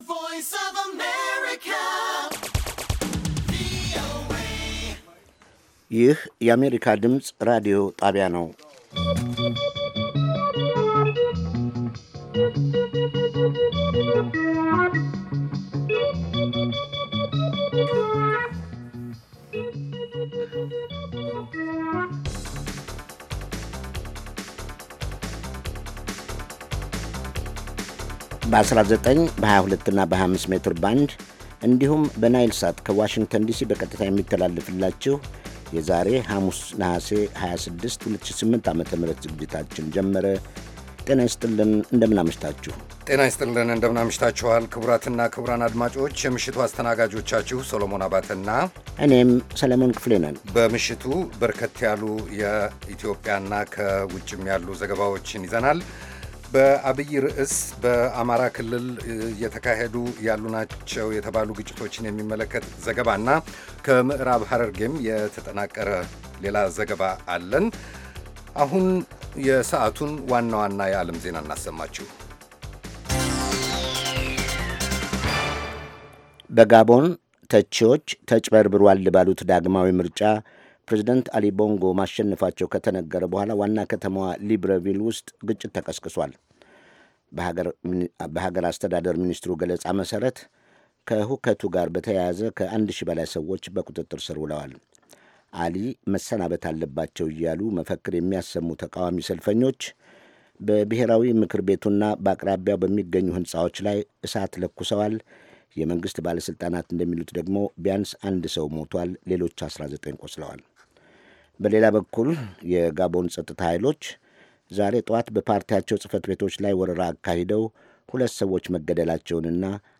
ከምሽቱ ሦስት ሰዓት የአማርኛ ዜና
ቪኦኤ በየዕለቱ ከምሽቱ 3 ሰዓት በኢትዮጵያ ኣቆጣጠር ጀምሮ በአማርኛ፣ በአጭር ሞገድ 22፣ 25 እና 31 ሜትር ባንድ የ60 ደቂቃ ሥርጭቱ ዜና፣ አበይት ዜናዎች ትንታኔና ሌሎችም ወቅታዊ መረጃዎችን የያዙ ፕሮግራሞች ያስተላልፋል። ሐሙስ፡- ባሕልና ማኅበረሰብ፣ ሕይወት በቀበሌ፣ የተፈጥሮ አካባቢ፣ ሣይንስና ሕይወት